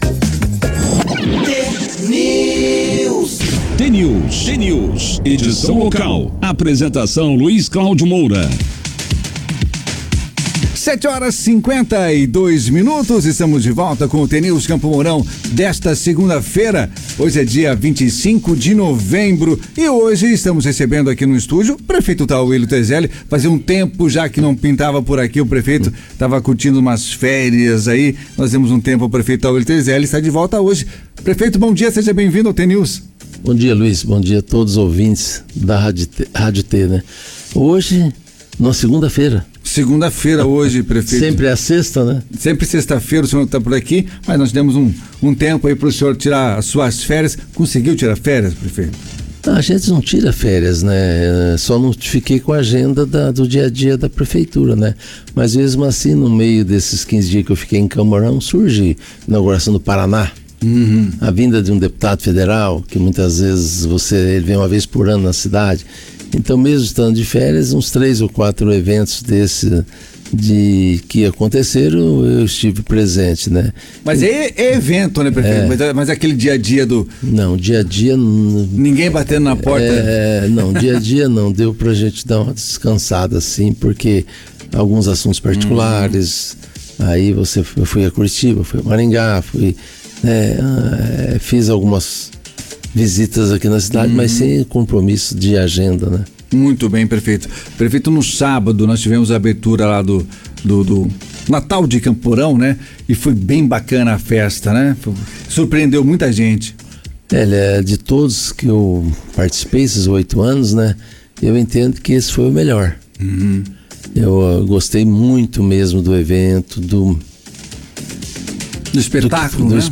O atual prefeito de Campo Mourão, Tauillo Tezelli, participou nesta segunda-feira, dia 25, do jornal T News da Rádio T FM.
Chegada de Papai Noel e acendimento das luzes na Praça São José, abrindo a temporada de atividades culturais do projeto Campo Mourão Cidade Natal 2024 e obras em andamento no município, os principais temas da participação do prefeito mourãoense no programa. Clique no player abaixo e ouça a íntegra da entrevista.